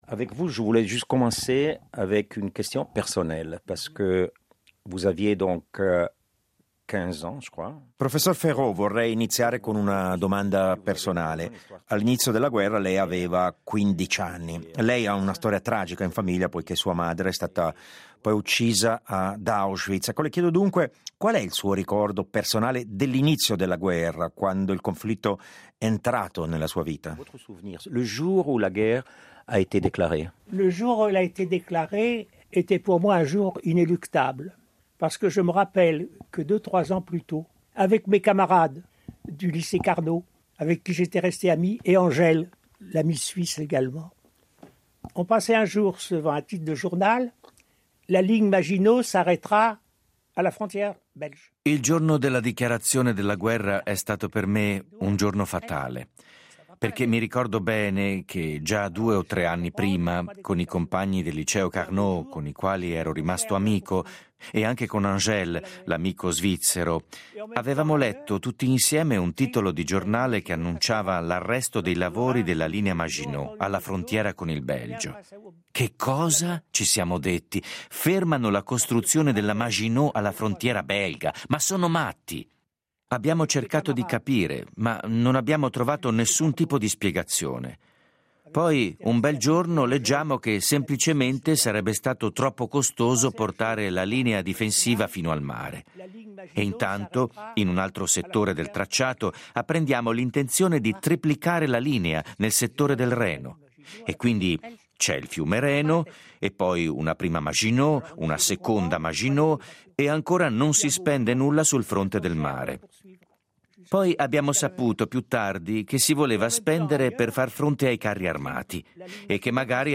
Tanti interrogativi ai quali Marc Ferro, specialista della seconda guerra mondiale e della Russia, e che fu condirettore della rivista “Les annales”, risponde in qualità di esperto ma anche di testimone.